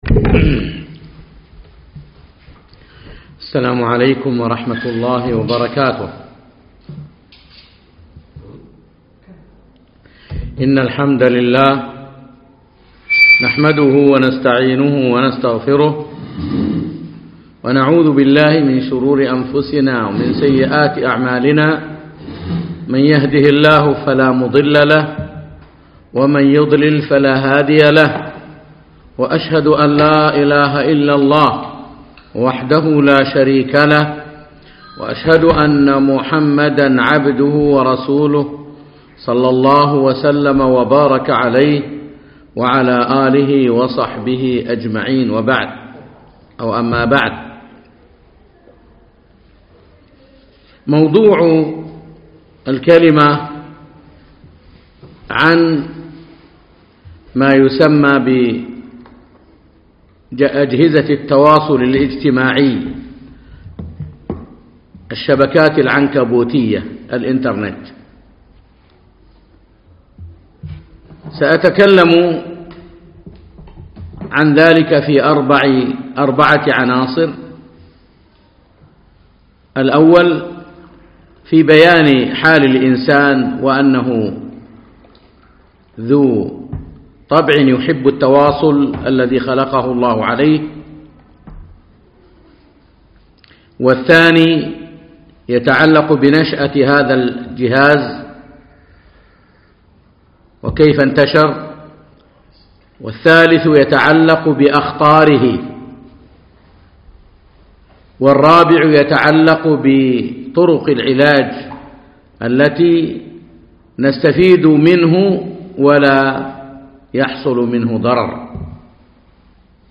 يوم الأثنين 6 رجب 1438 الموافق 3 4 2017 في مركز القرين نسائي مسائي القصور
ضوابط التعامل مع وسائل التواصل - محاضرة